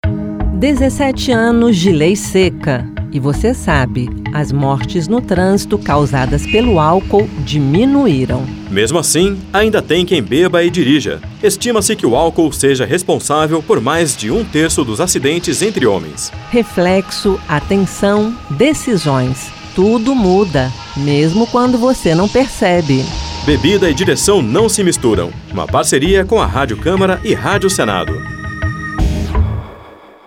Spots e Campanhas